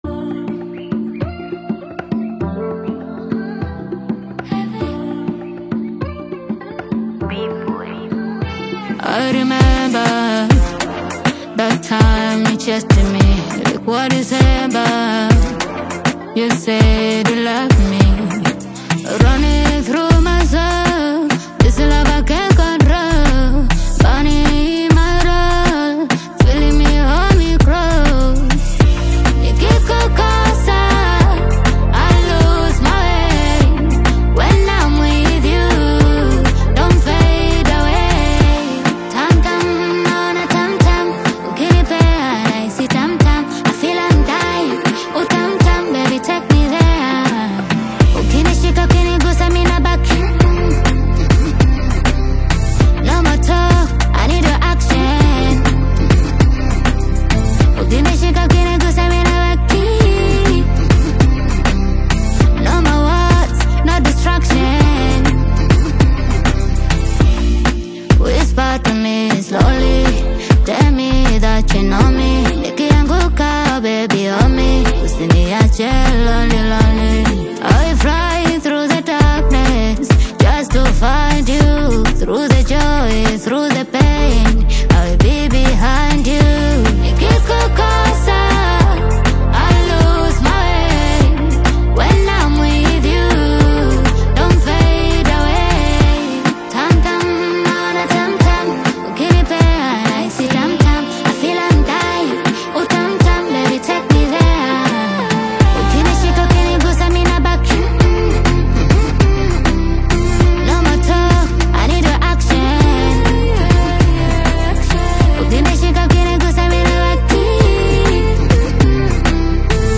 catchy Tanzanian Bongo Flava/Afro-Pop single
blends melodic hooks with upbeat Afro-inspired production
expressive vocals and modern Tanzanian sound
Afrobeat